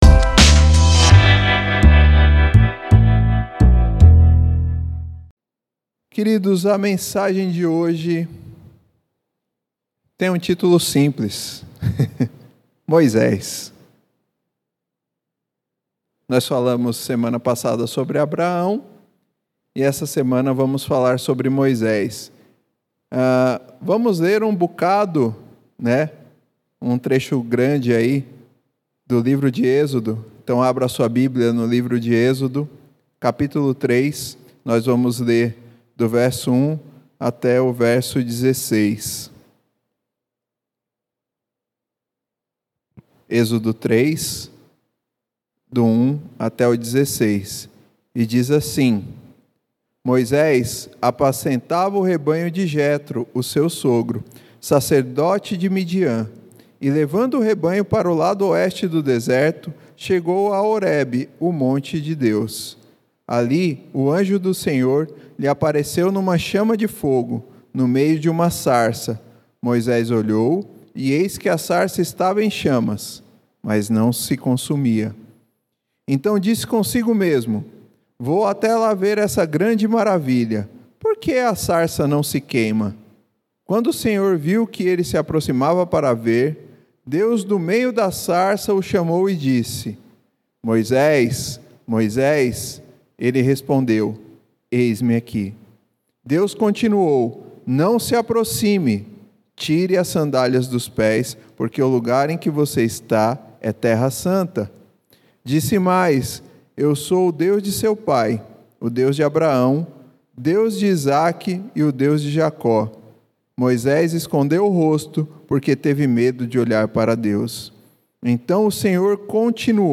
Mensagem realizado nos encontros de Reflexão de Oração às Quintas-Feiras 20h.